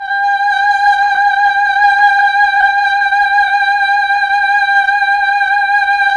VOX_Chb Fm G_6-L.wav